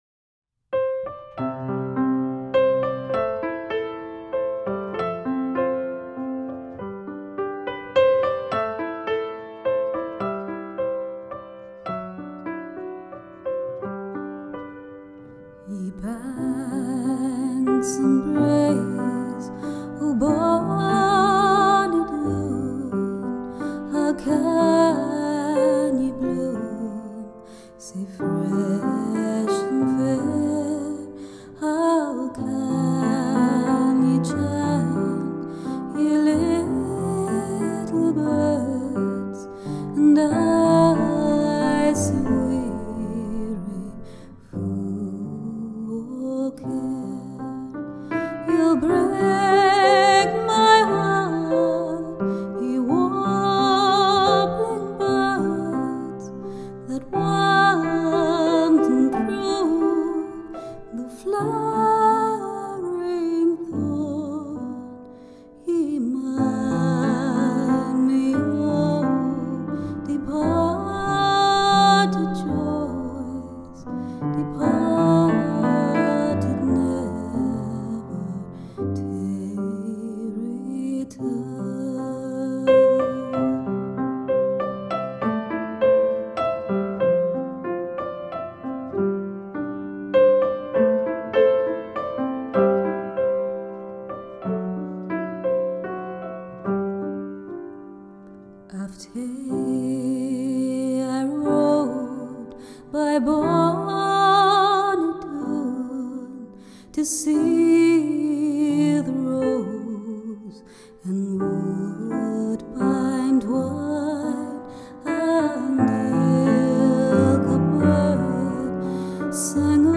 String Musicians